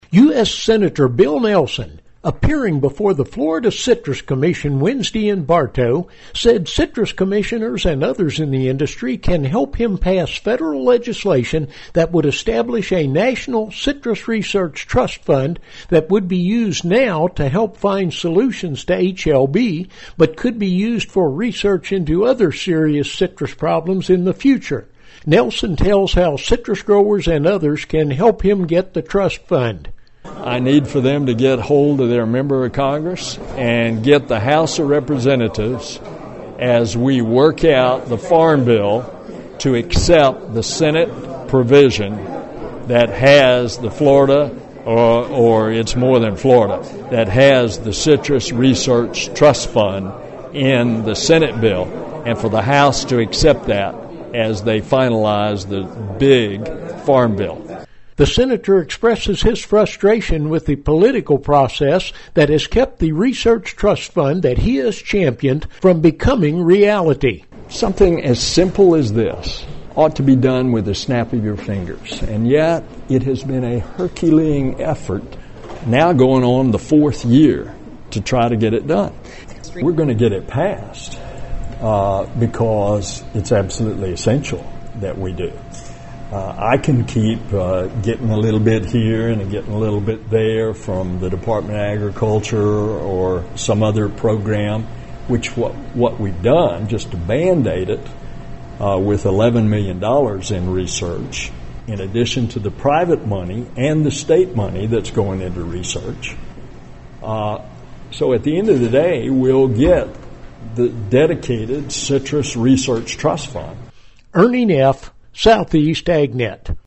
U.S. Senator Bill Nelson told the Florida Citrus Commission Wednesday how the Florida citrus industry can help him pass federal legislation establishing a National Citrus Research Trust Fund to help find solutions to HLB.